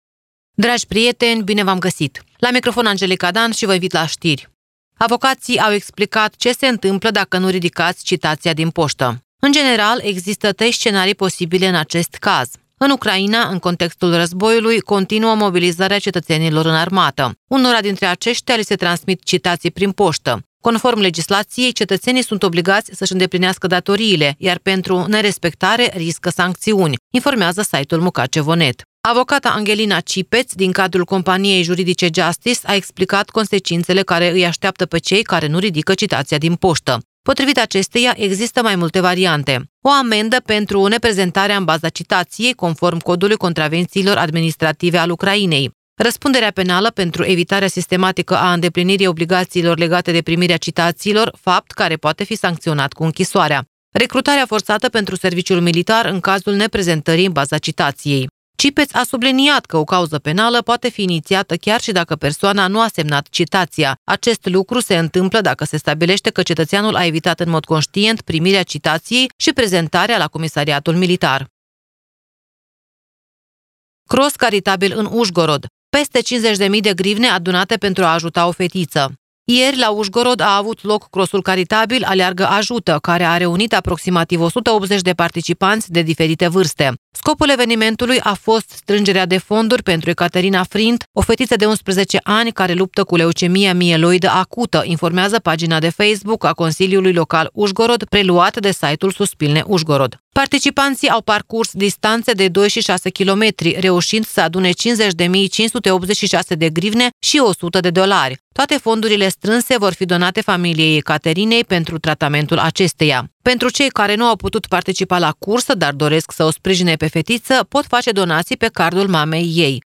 Ştiri Radio Ujgorod – 09.12.2024